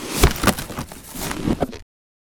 barrel.wav